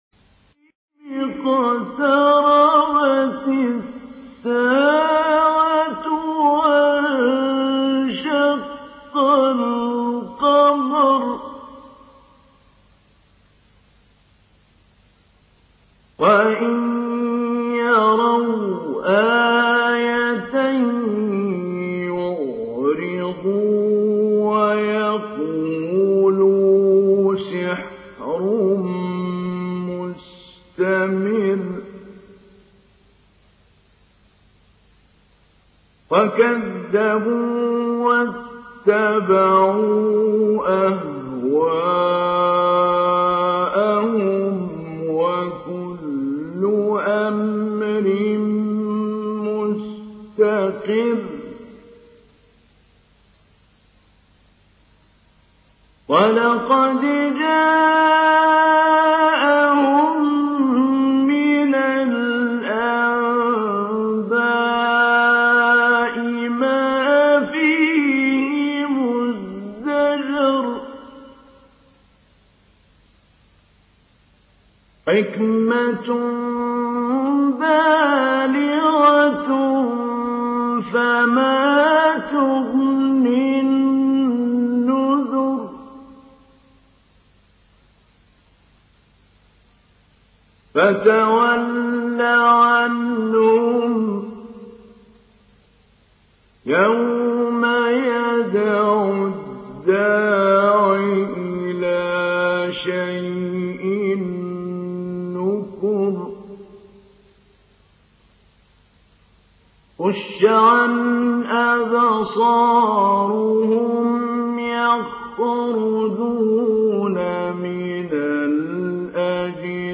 Surah Kamer MP3 by Mahmoud Ali Albanna Mujawwad in Hafs An Asim narration.